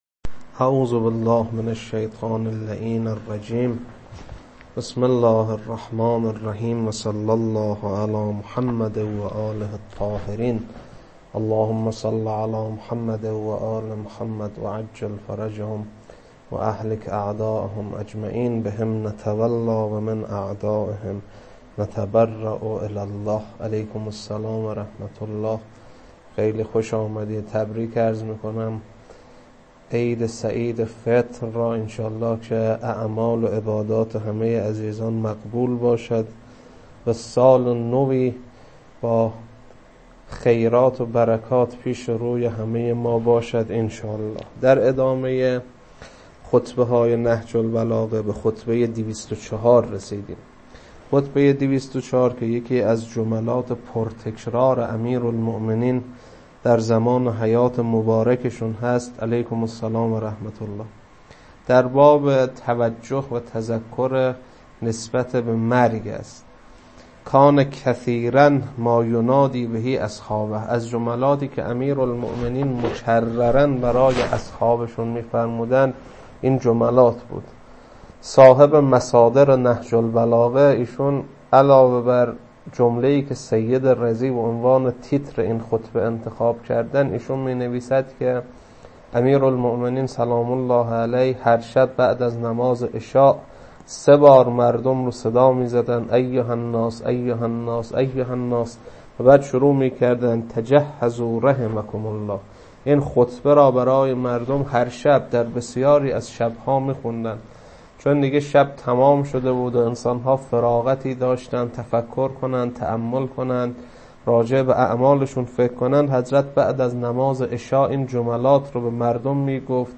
خطبه-204.mp3